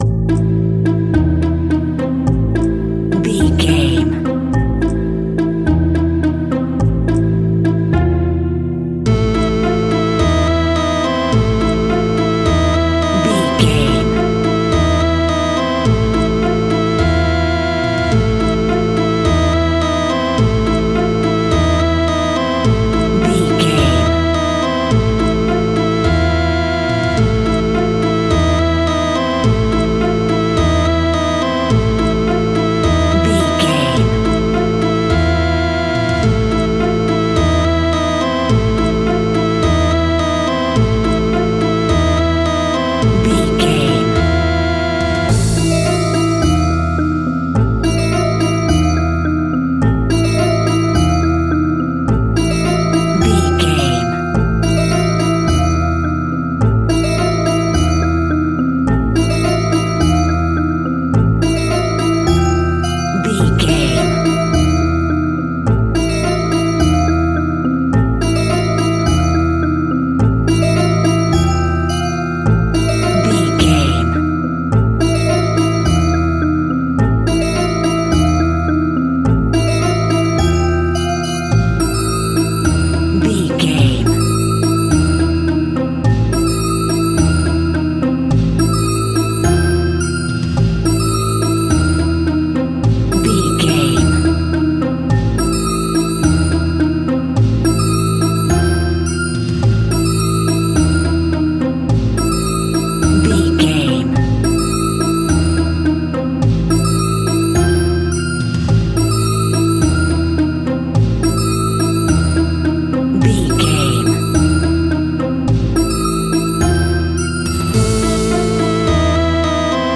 Aeolian/Minor
scary
ominous
dark
suspense
eerie
playful
strings
drums
synthesiser
piano
percussion
horror
spooky